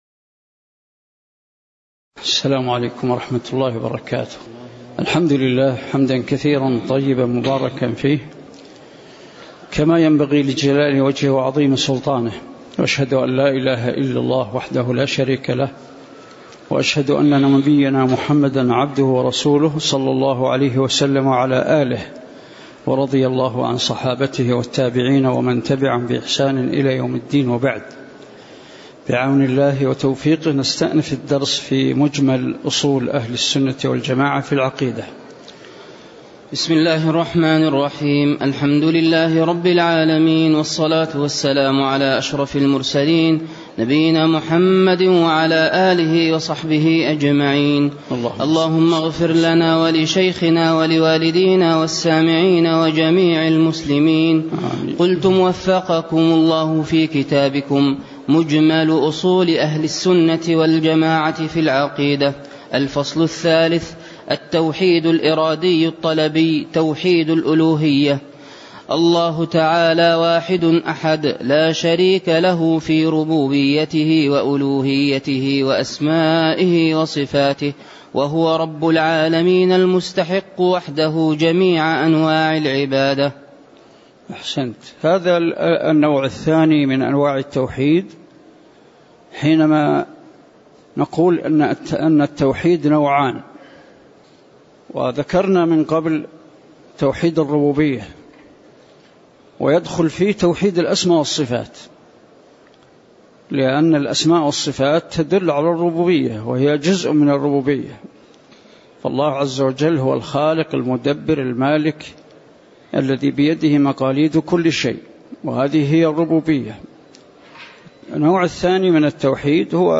تاريخ النشر ٣٠ ربيع الثاني ١٤٣٩ هـ المكان: المسجد النبوي الشيخ